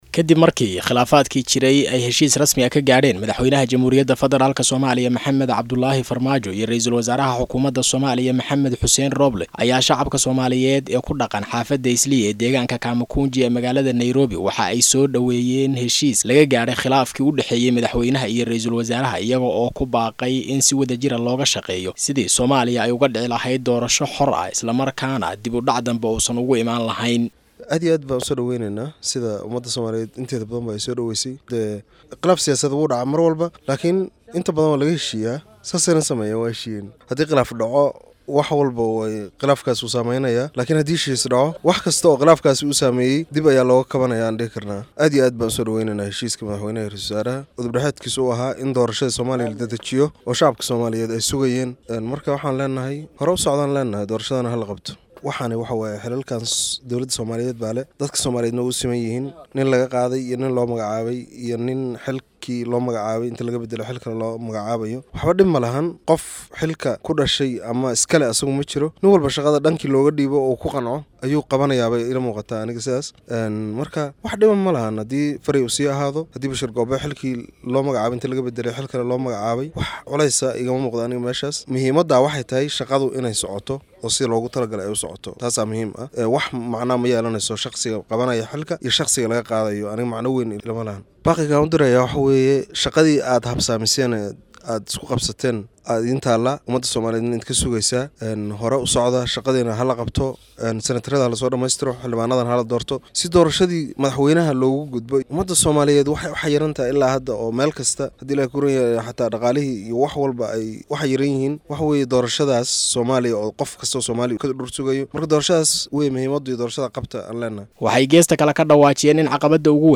Qaar ka mid ah shacabka ku nool xaafadda Islii ee deegaanka Kamkunji magaalada Nairobi ayaa aragtidooda ka dhiibtay xalka laga gaaray kala aragti